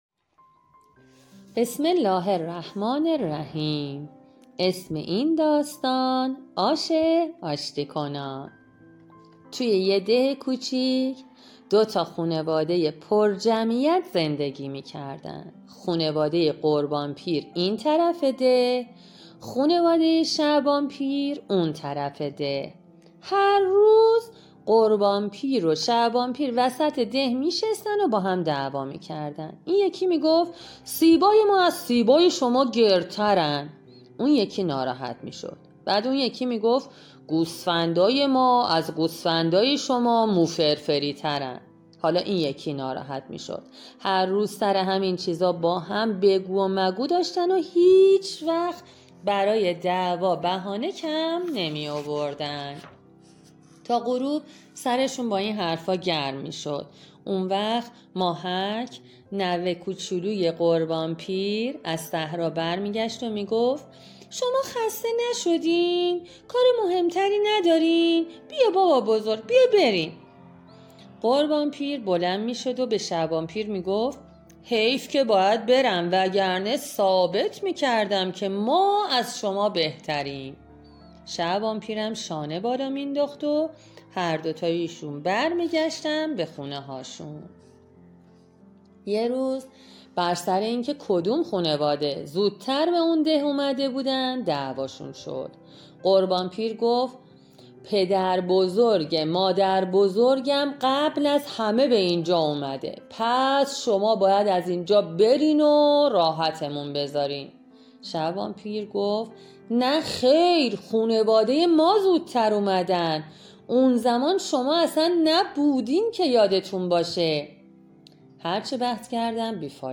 از شما دعوت می‌کنیم تا در تعطیلات نوروزی از کتاب های صوتی کتابخانه استفاده کنید.